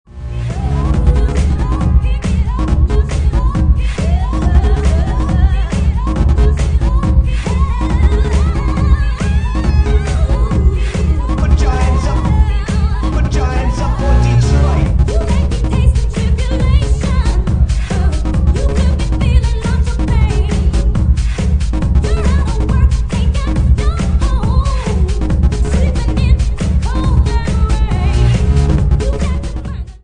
at 69 bpm